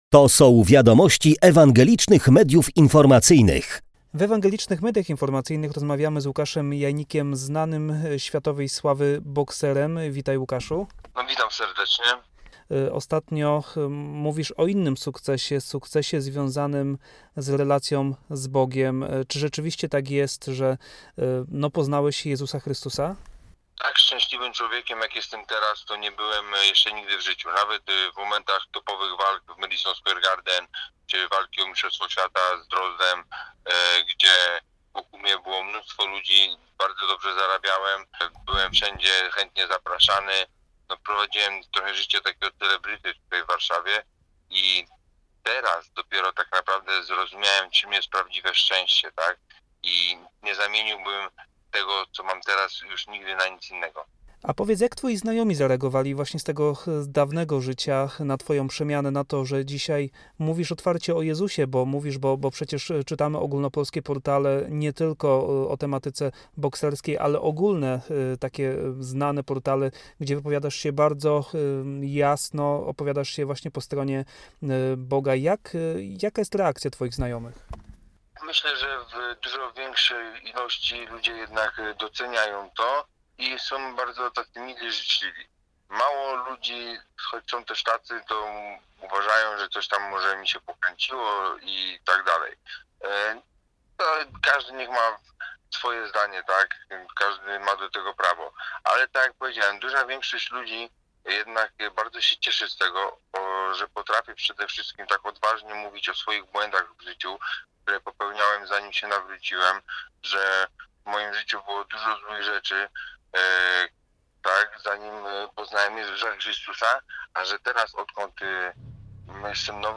Łukasz-Janik-rozmowa.mp3